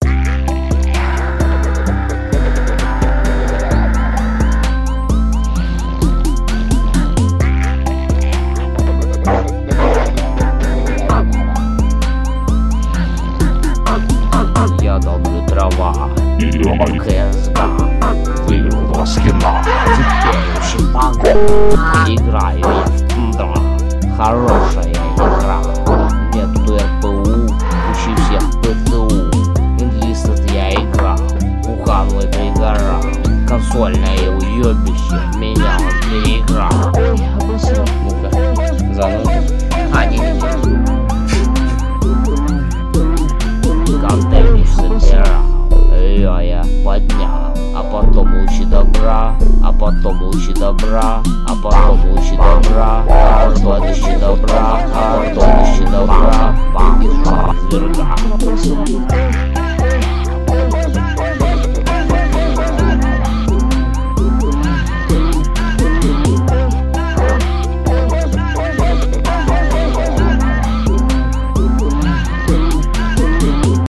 Personally, I'm far from being involved in the craft of music production, but two of my friends make some pretty good beats.